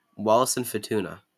Wallis and Futuna, officially the Territory of the Wallis and Futuna Islands[A][3] (/ˈwɒlɪs ...fˈtnə/